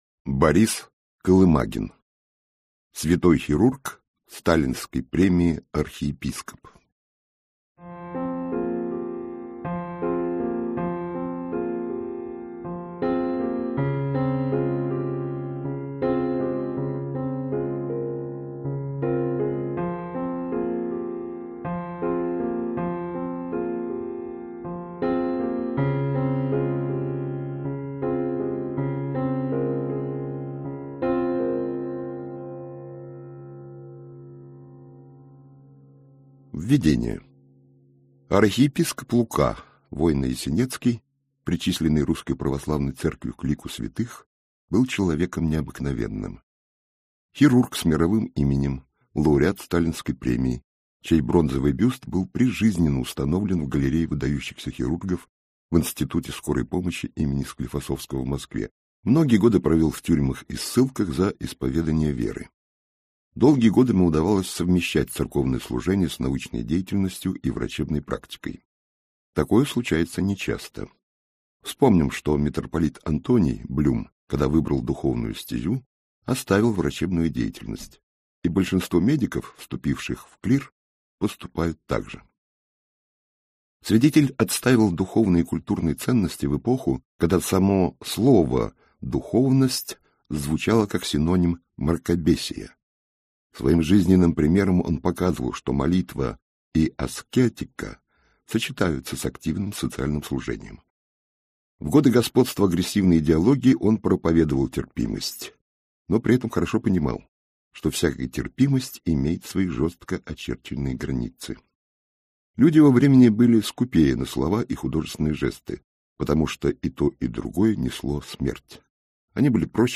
Аудиокнига Святой хирург. Жизнь и судьба архиепископа Луки (Войно-Ясенецкого) | Библиотека аудиокниг